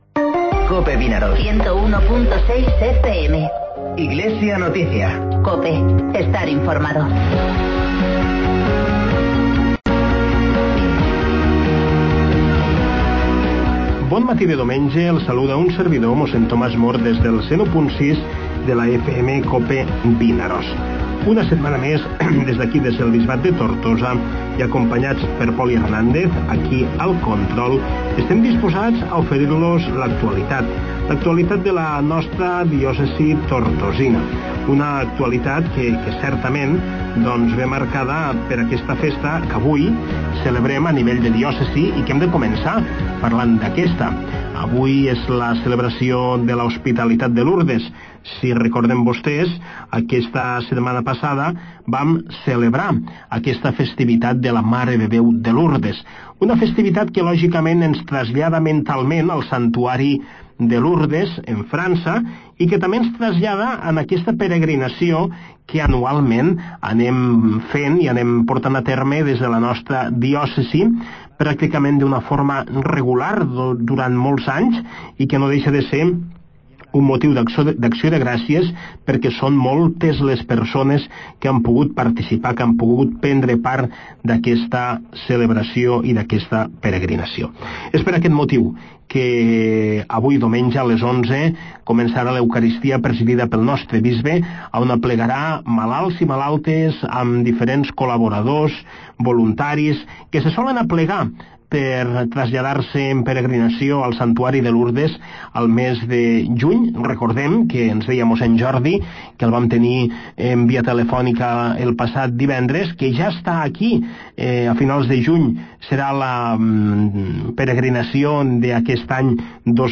AUDIO: Espai informatiu del Bisbat de Tortosa, tots els diumenges de 9:45 a 10 hores.